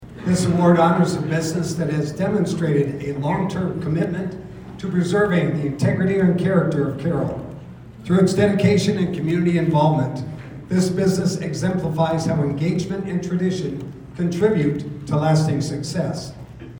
The Carroll Chamber of Commerce hosted its annual banquet Thursday evening and presented awards to community leaders, volunteers, and local businesses. One of the businesses to be recognized was Security Title and Investment with the Heritage Business Award.